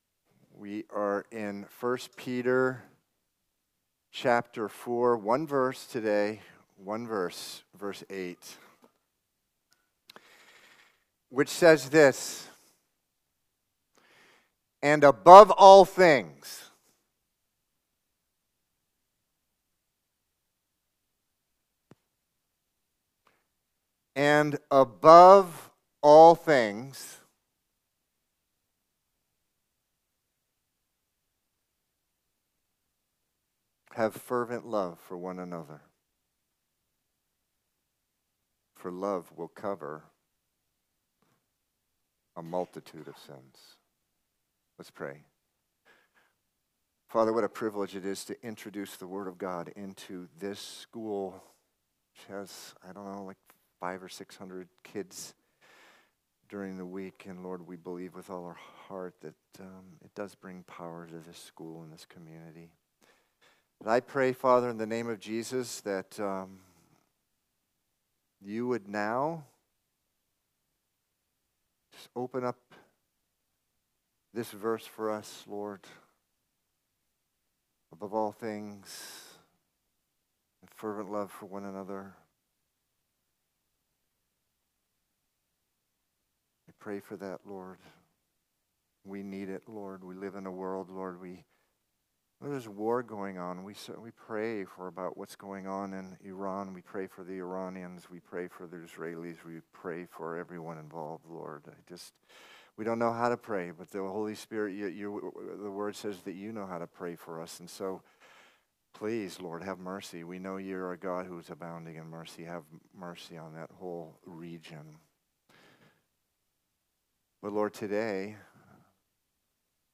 Listen to Sunday messages from Calvary Chapel In The City, located in Boston, MA.